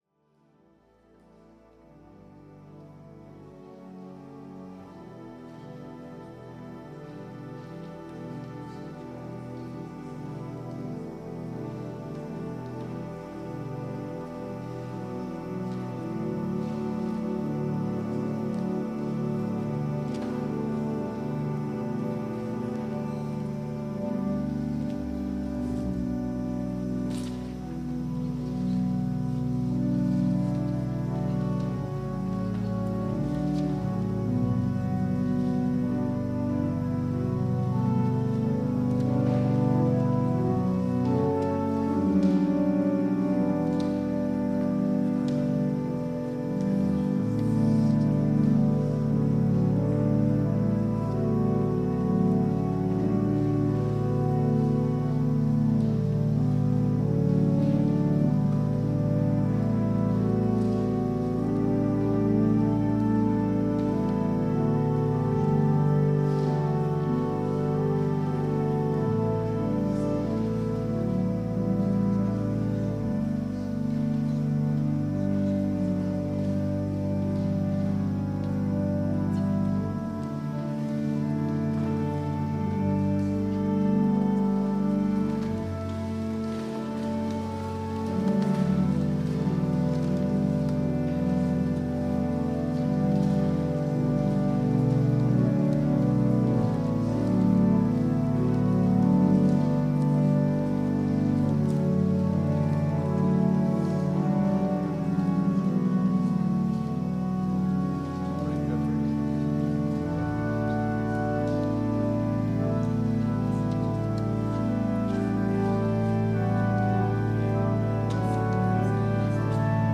Worship and Sermon audio podcasts
WORSHIP - 10:30 a.m. Trinity Sunday